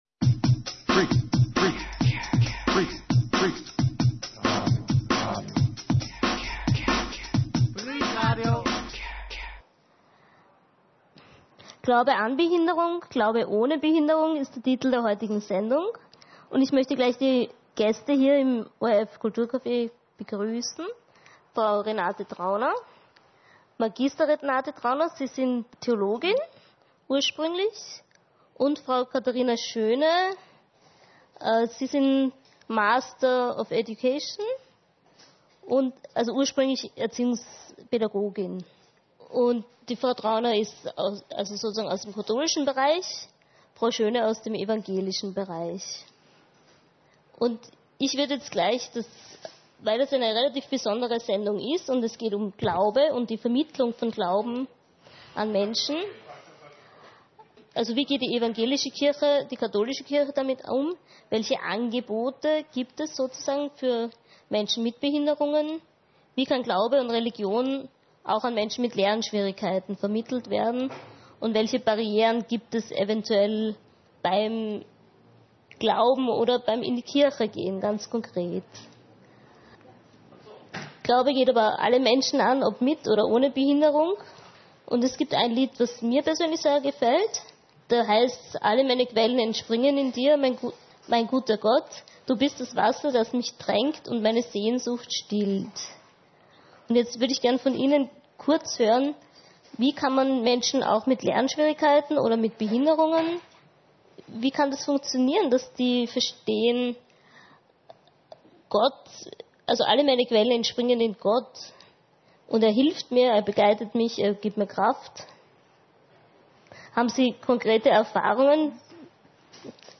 Diese Sendung können Sie hier kostenlos nachhören.